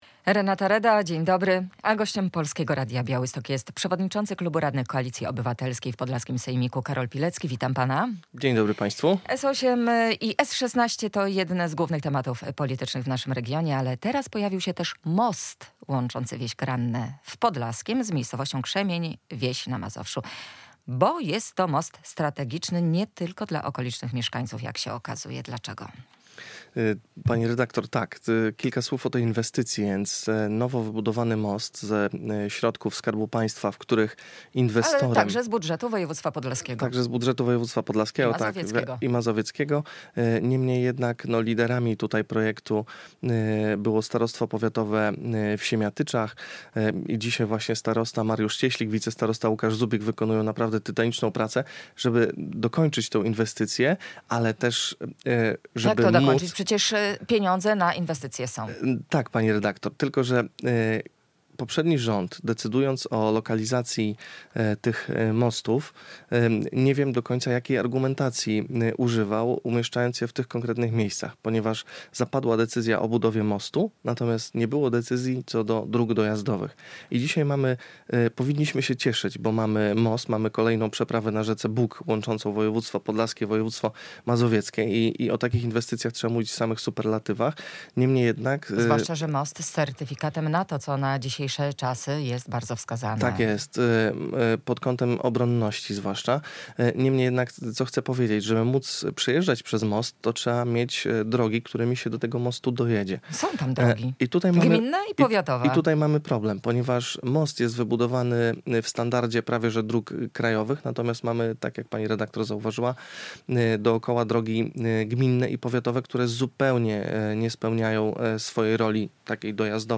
Jakie znaczenie ma ta inwestycja? O tym w środowej (5.02) Rozmowie Dnia z przewodniczącym klubu radnych Koalicji Obywatelskiej w podlaskim sejmiku Karolem Pileckim.